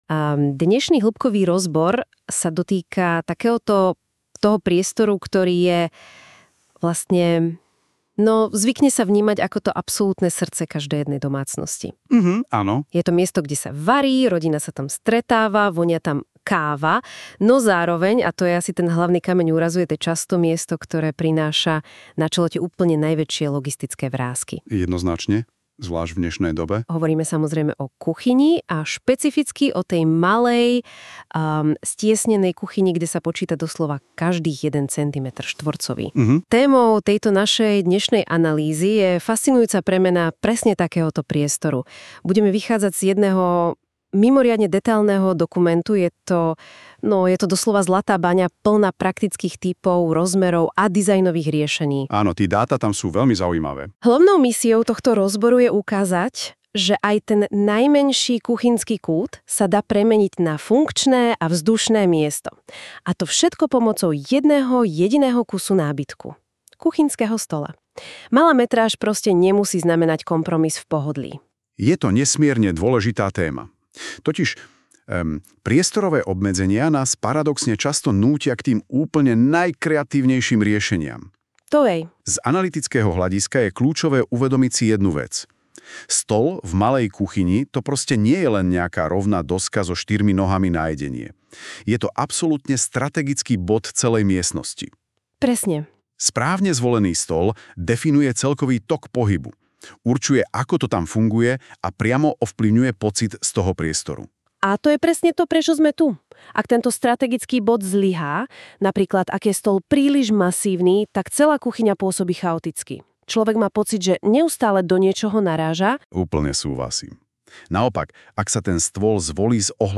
Vypočujte si dialóg a započúvajte sa do témy výberu kuchynského stola do malej kuchyne.